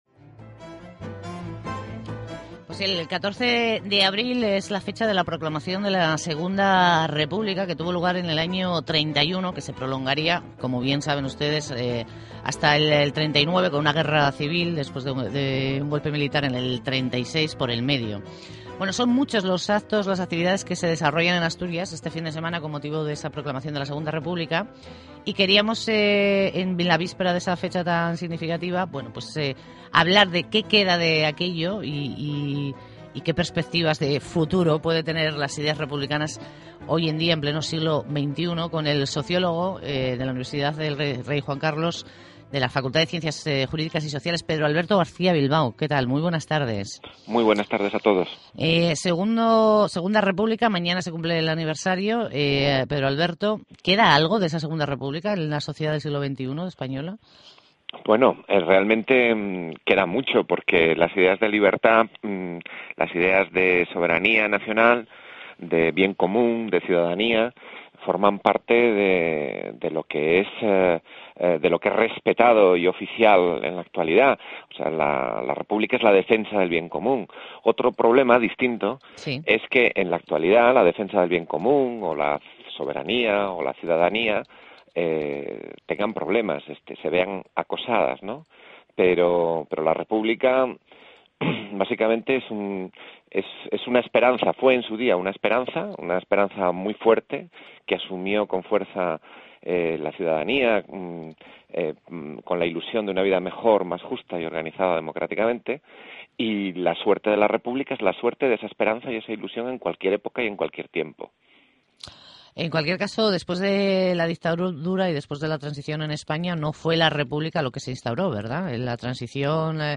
El 13 de abril de 2012, la Radio Pública de Asturias en el programa La buena tarde, me hizo una amplia entrevista sobre la cuestión republicana. Se habló del presente, del futuro y de las libertades y derechos de todos, de las diferencias entre liberalismo y republicanismo, de lo que son los valores republicanos, de lo que fue aquel Abril de libertad y de los que son las ilusiones de paz y libertad para el futuro de todos hoy. Fue una entrevista tranquila, serena, sincera.